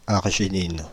Ääntäminen
France (Île-de-France): IPA: /aʁ.ʒi.nin/